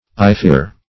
Ifere \I*fere"\, a.